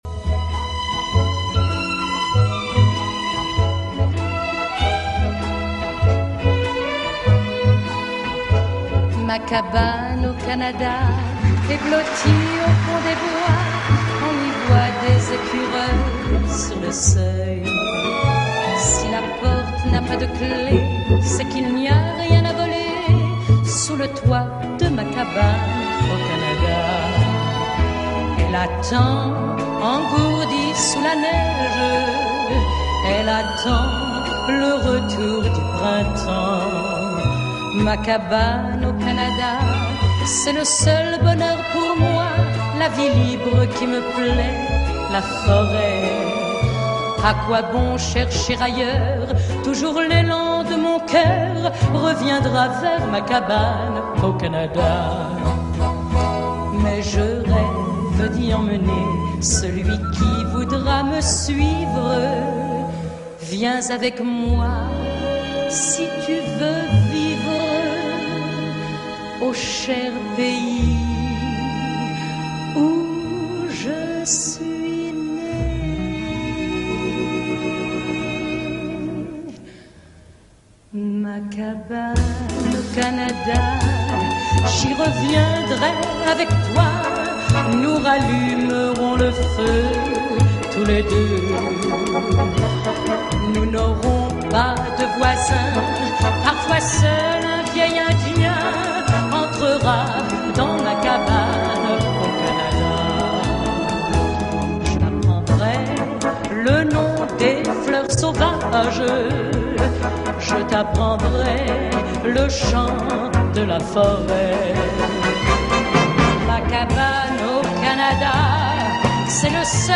Ça gratte, le son est un peu tordu, mais c’est magique.